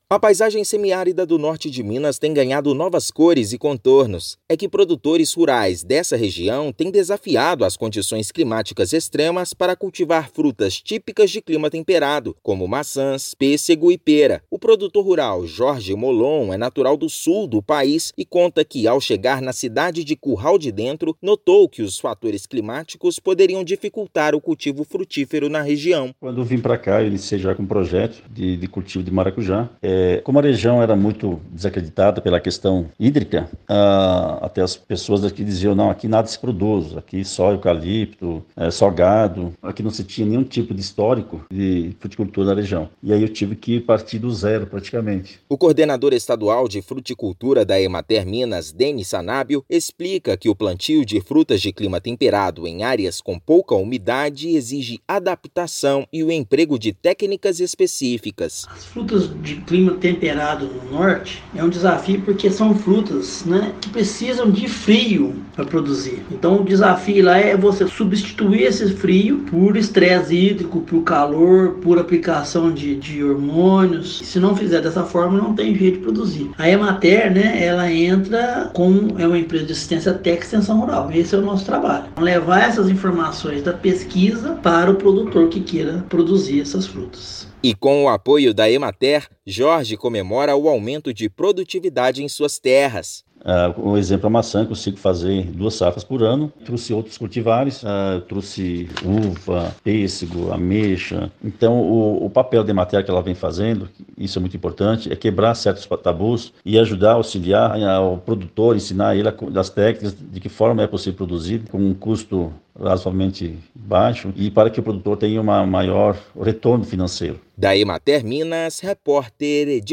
[RÁDIO] Técnicas de fruticultura favorecem o cultivo de frutas de clima temperado no Norte de Minas
Produtores apostam em peras, maçãs e pêssegos, graças ao apoio da Emater-MG. Ouça matéria de rádio.